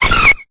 Cri de Mélo dans Pokémon Épée et Bouclier.